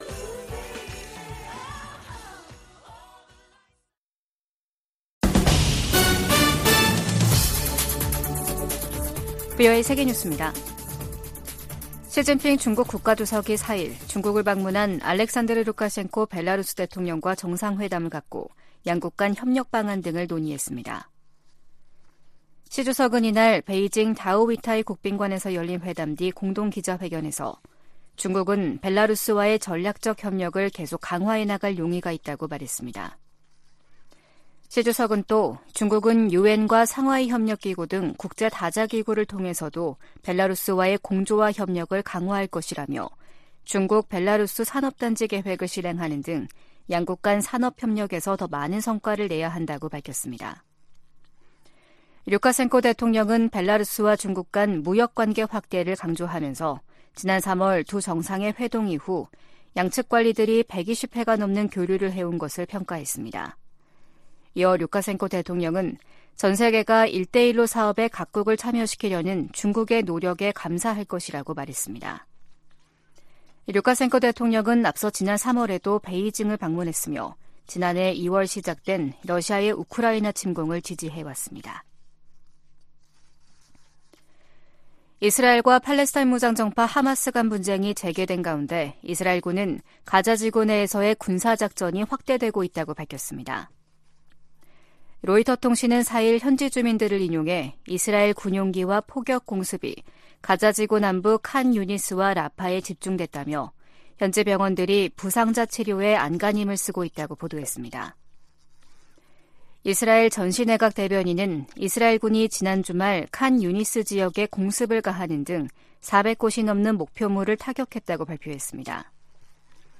VOA 한국어 아침 뉴스 프로그램 '워싱턴 뉴스 광장' 2023년 12월 5일 방송입니다. 북한에 이어 한국도 첫 군사정찰위성 발사에 성공하면서 남북한 간 위성 경쟁이 치열해질 전망입니다.